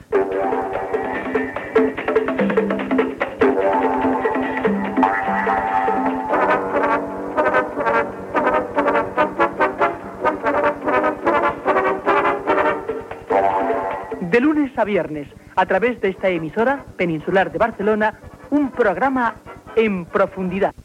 Careta de sortida del programa